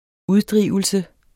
Udtale [ -ˌdʁiˀvəlsə ]